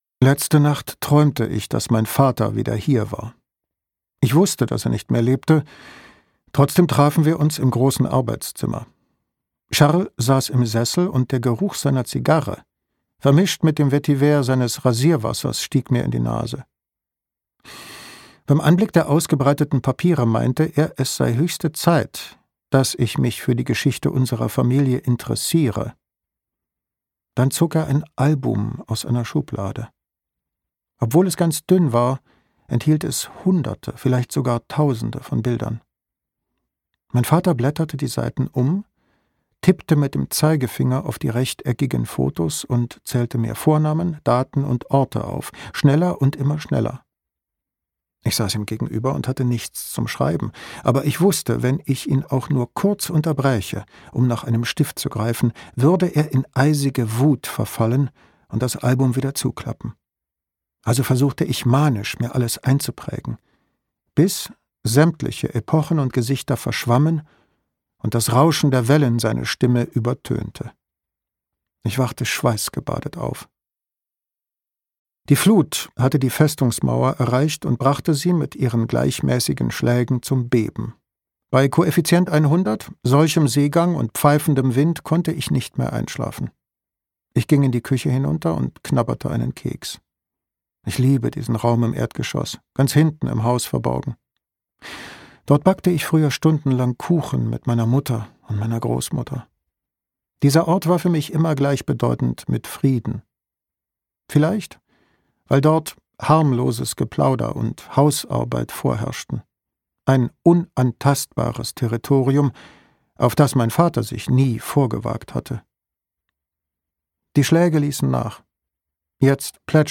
Ein wunderschönes Hörbuch über Aufarbeitung, Versöhnung und die heilende Kraft des Meeres.
Gekürzt Autorisierte, d.h. von Autor:innen und / oder Verlagen freigegebene, bearbeitete Fassung.